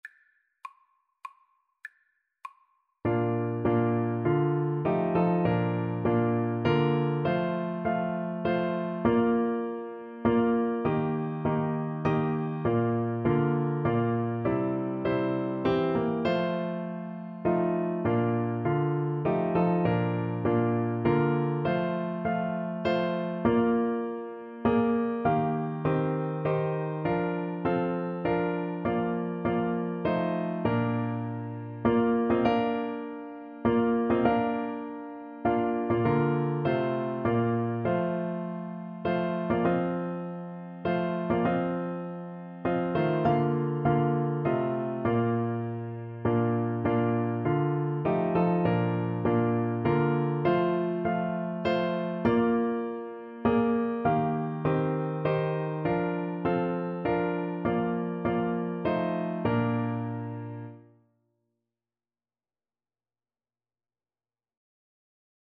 3/4 (View more 3/4 Music)
Traditional (View more Traditional French Horn Music)